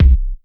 Kick_13.wav